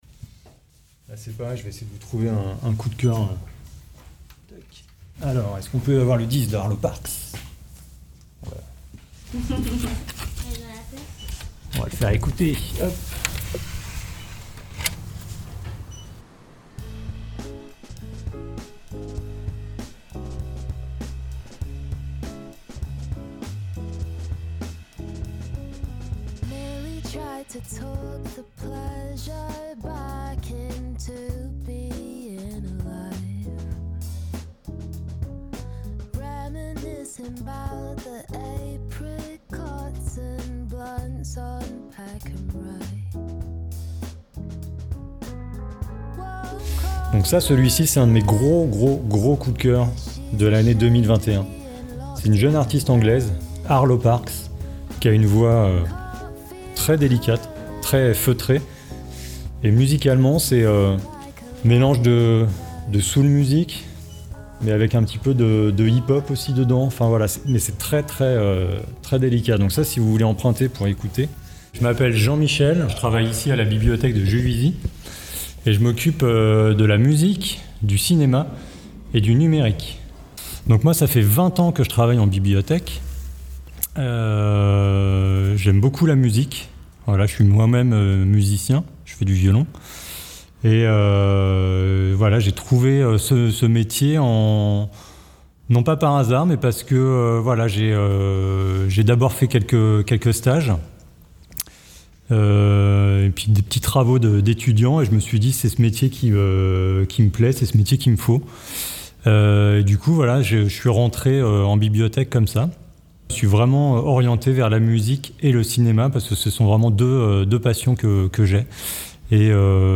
Reportage médiathèque